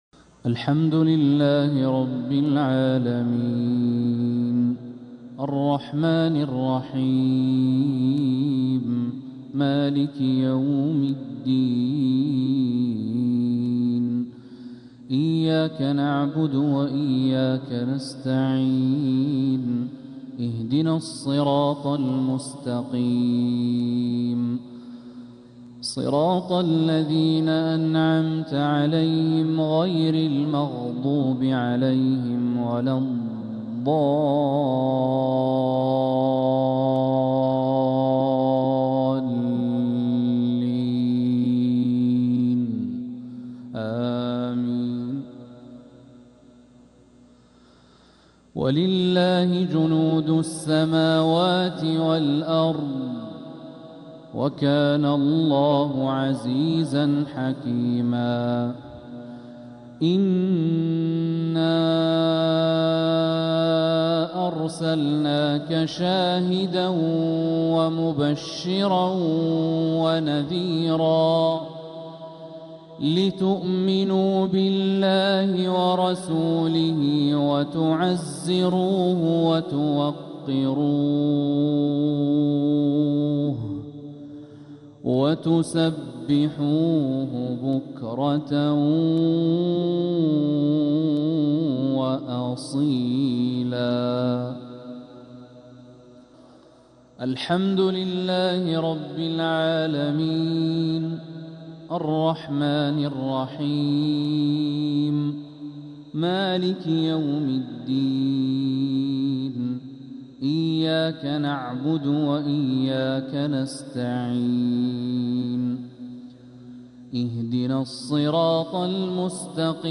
مغرب الثلاثاء 6 محرم 1447هـ من سورة الفتح 7-9 و الكوثر كاملة | Maghrib prayer from Surah al-Fath and Al-Kawthar 1-7-2025 > 1447 🕋 > الفروض - تلاوات الحرمين